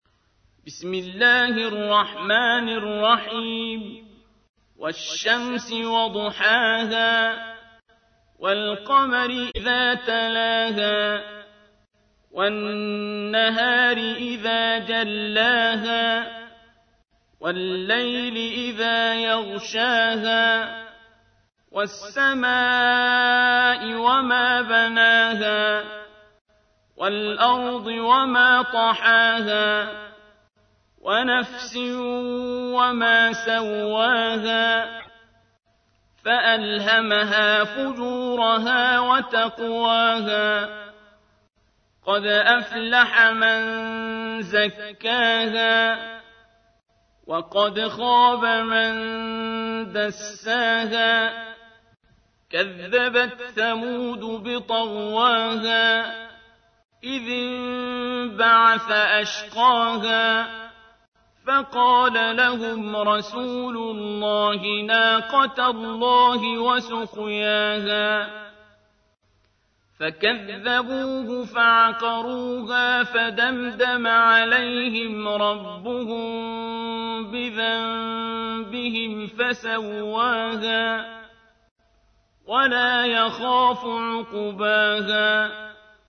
تحميل : 91. سورة الشمس / القارئ عبد الباسط عبد الصمد / القرآن الكريم / موقع يا حسين